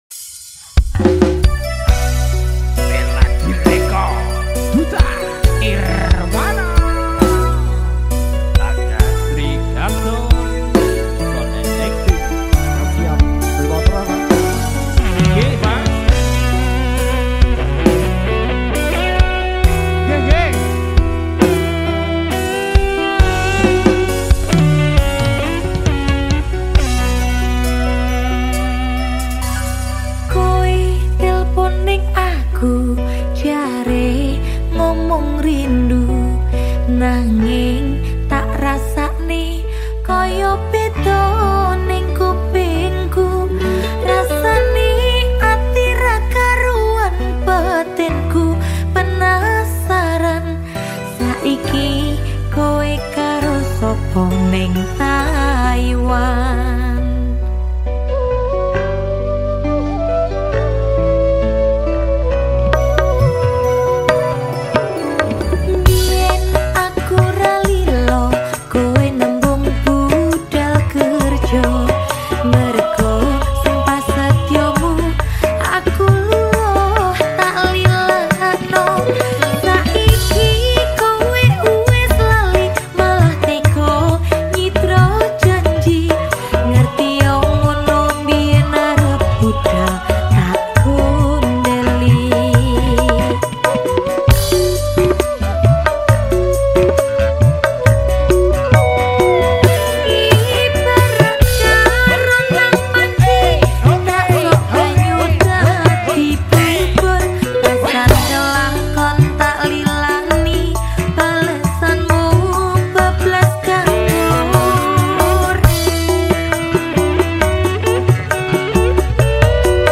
Dangdut Koplo